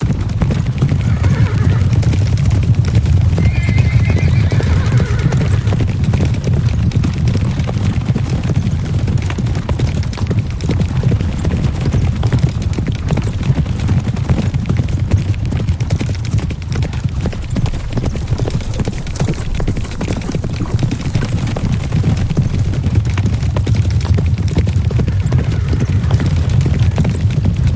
На этой странице собраны натуральные звуки копыт лошадей: от размеренного шага до энергичного галопа.
Звук скачущих лошадей топот копыт